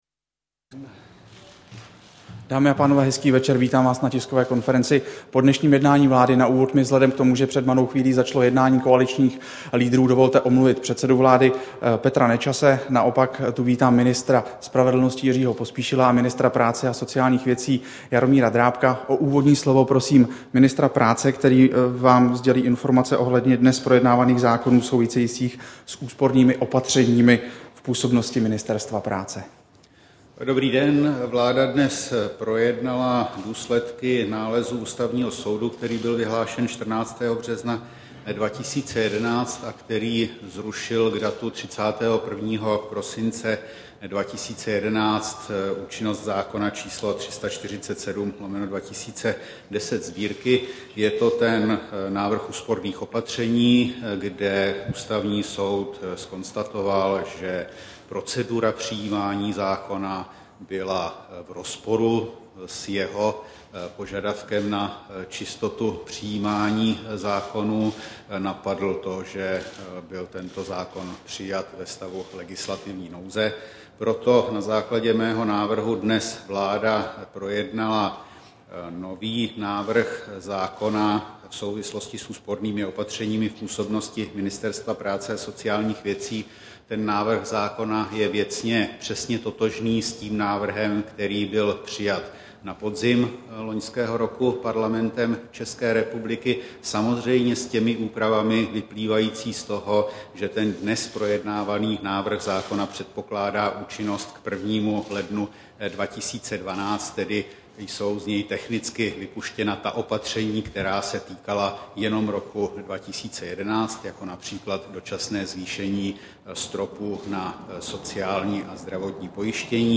Tisková konference po jednání vlády, 23. března 2011